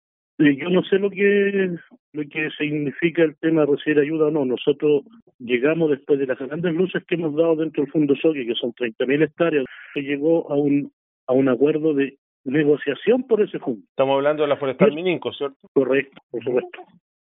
En conversación con Radio Bío Bío, el también candidato a constituyente insistió en diferenciarse del líder de la Coordinadora Arauco Malleco, especialmente en lo que tiene que ver con la forma de reivindicar las tierras ancestrales.